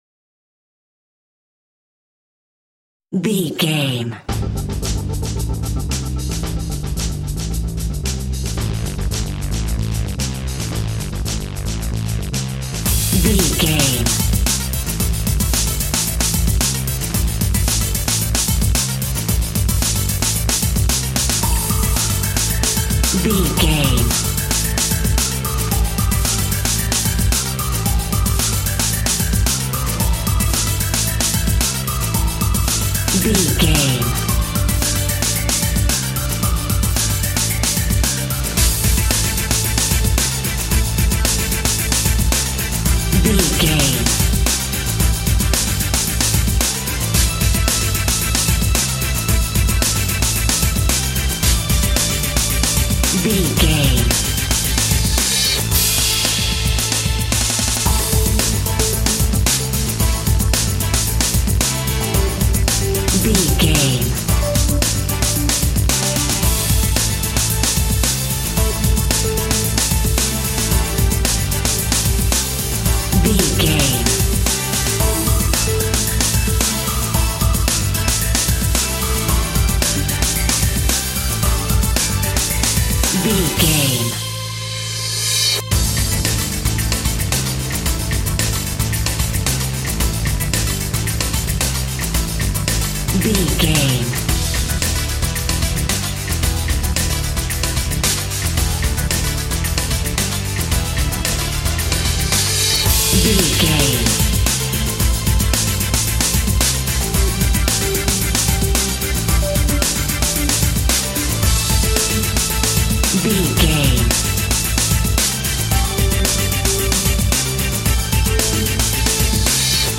Aeolian/Minor
funky
smooth
groovy
driving
synthesiser
drums
strings
electronica
cinematic